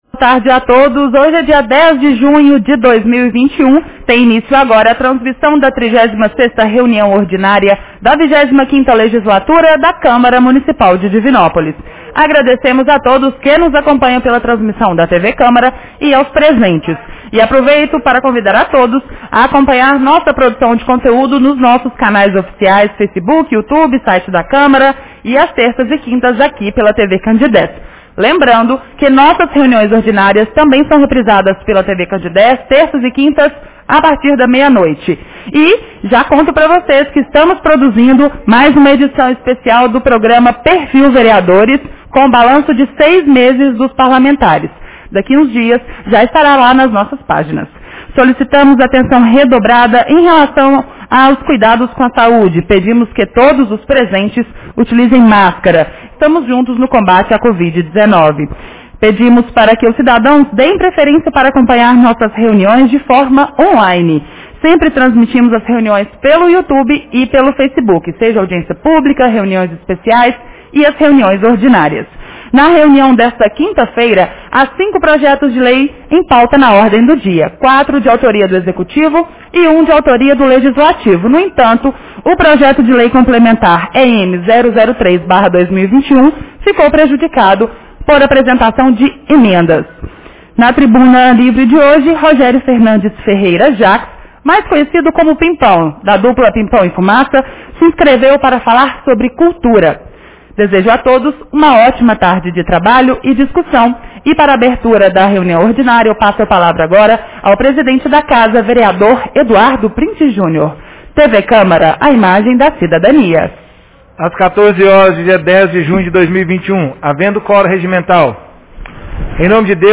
Reunião Ordinária 36 de 10 de junho 2021